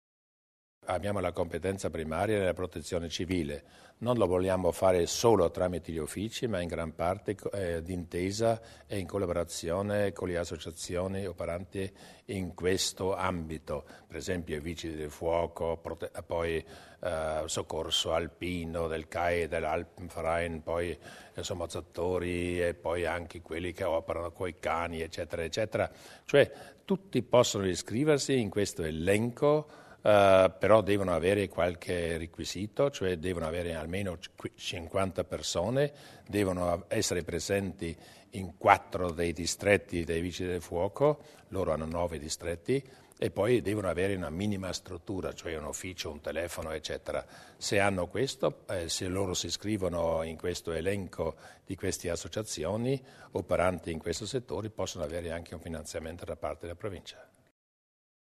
Di seguito alcune delle decisioni assunte dalla Giunta provinciale nella seduta di oggi (19 ottobre) e illustrate dal presidente Luis Durnwalder nella successiva conferenza stampa.